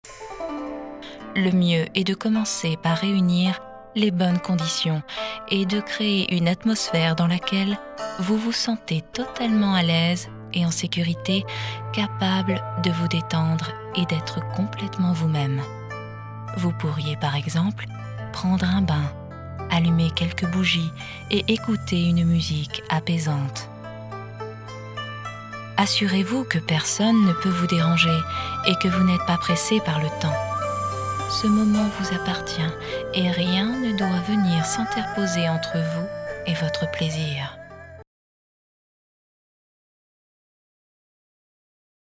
Sprechprobe: Sonstiges (Muttersprache):
french female professional voice over , I gave my voice for several projects : E.learning, TV spot , Jingles, Commentary, Audioguides, Voiceover ...